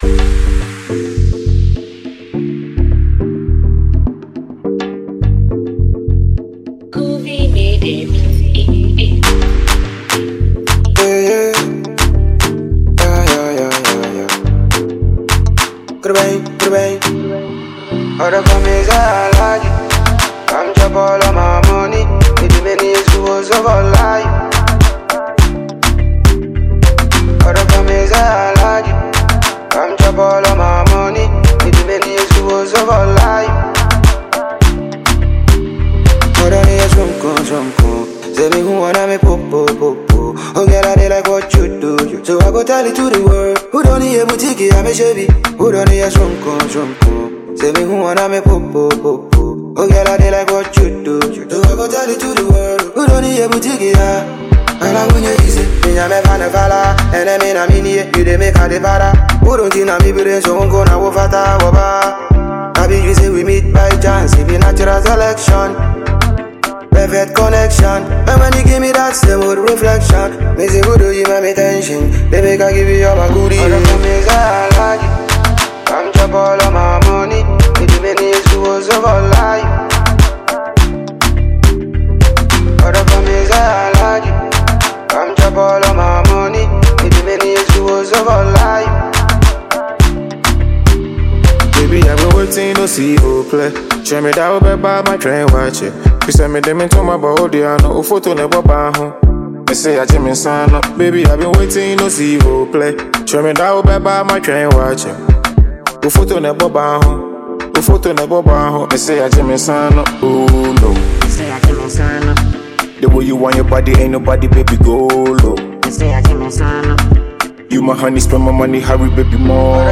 Ghana MusicMusic
Ghanaian Music Duo